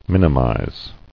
[min·i·mize]